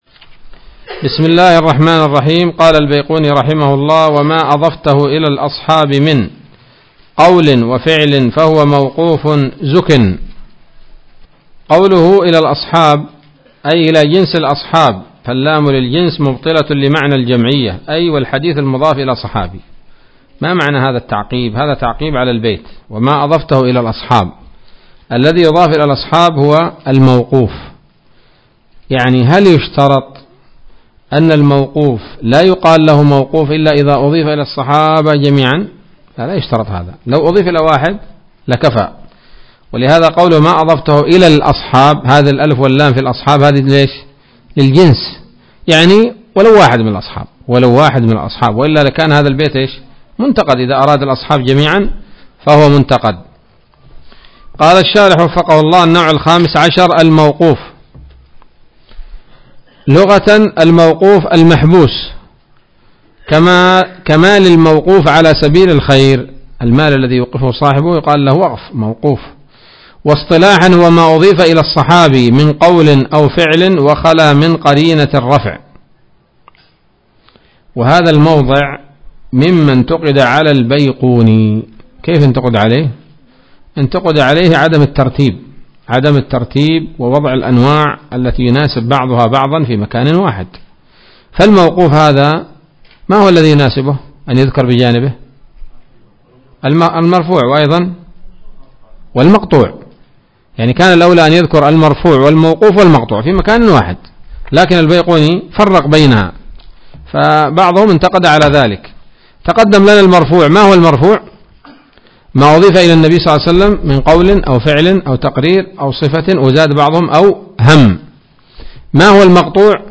الدرس الحادي والعشرون من الفتوحات القيومية في شرح البيقونية [1444هـ]